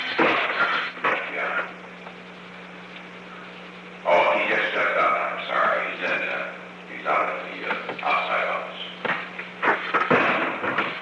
Recording Device: Oval Office
On December 7, 1971, President Richard M. Nixon and Harold H. Saunders met in the Oval Office of the White House at an unknown time between 4:29 pm and 4:33 pm. The Oval Office taping system captured this recording, which is known as Conversation 631-005 of the White House Tapes.